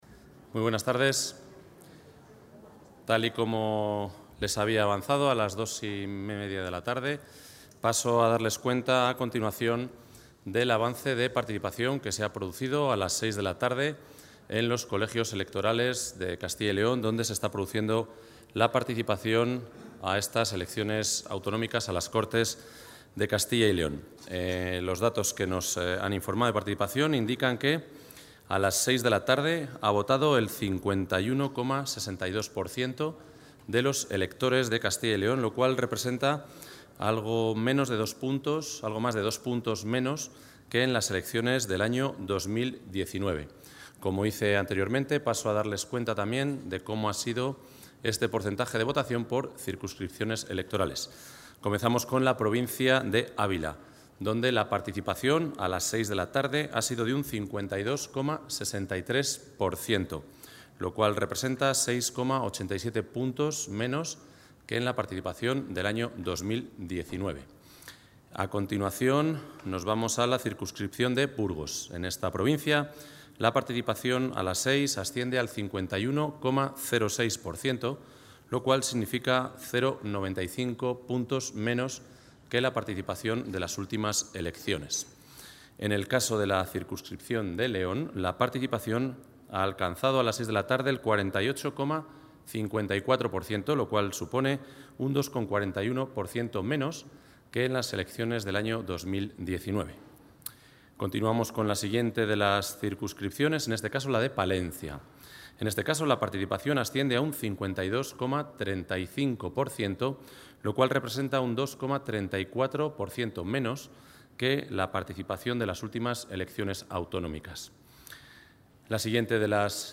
Rueda de prensa.
El consejero de la Presidencia, Ángel Ibáñez, ha informado en rueda de prensa de los datos de participación a las 18.00 horas.